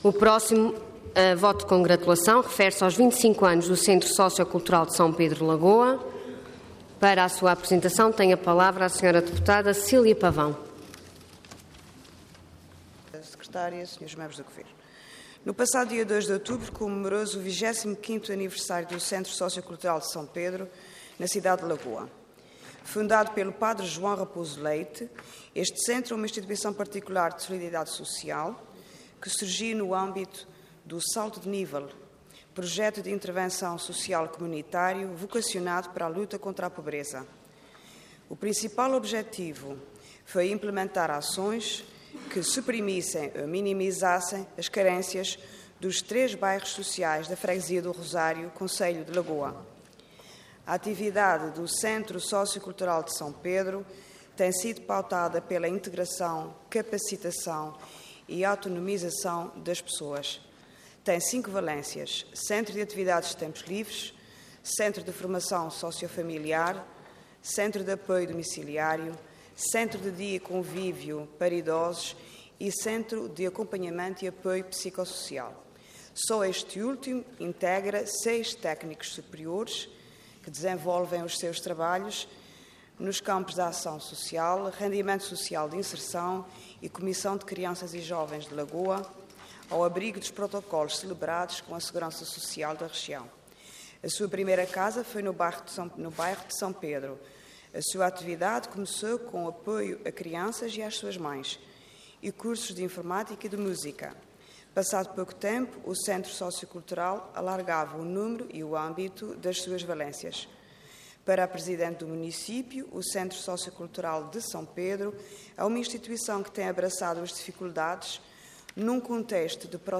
Detalhe de vídeo 10 de dezembro de 2015 Download áudio Download vídeo Processo X Legislatura 25 Anos do Centro Sócio Cultural de S. Pedro - Lagoa Intervenção Voto de Congratulação Orador Cecília Pavão Cargo Deputada Entidade PS